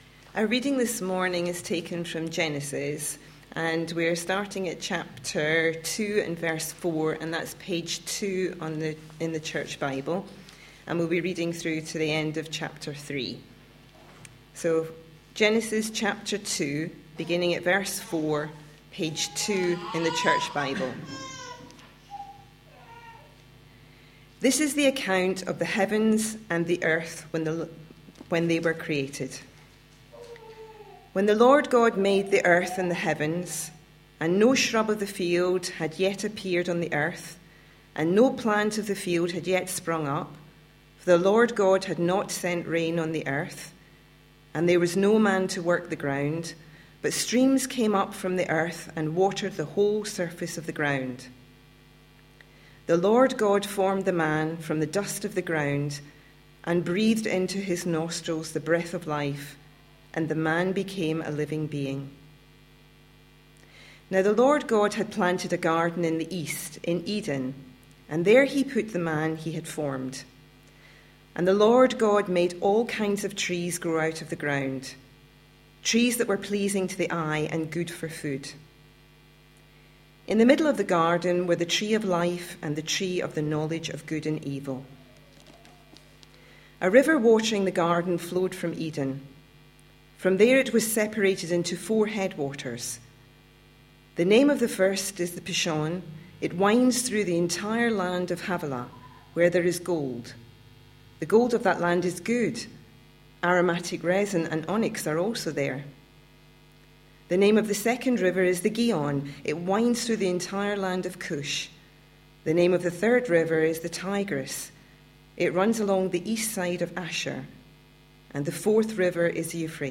A sermon preached on 4th May, 2014, as part of our Changing the way you think series.